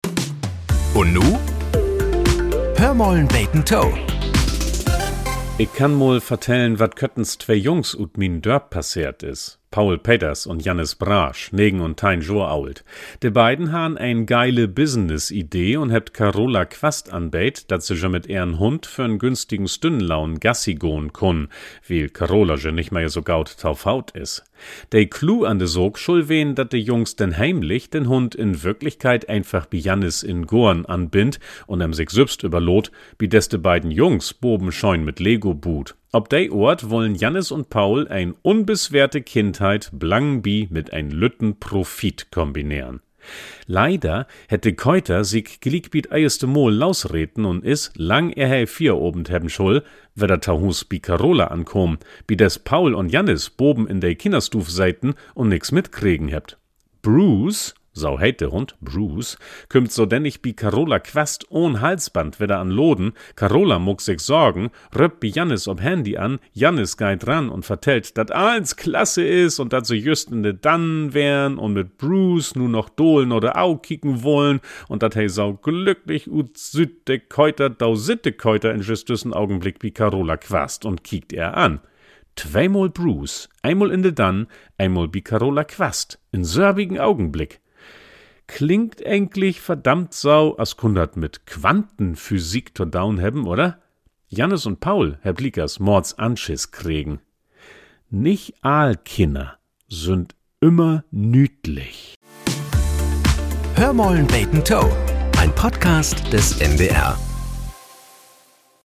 Nachrichten - 29.01.2025